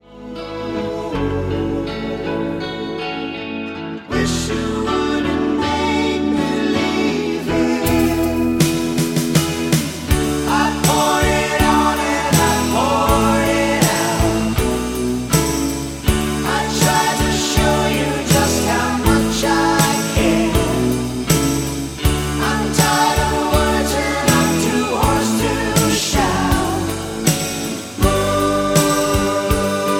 Backing track files: 1970s (954)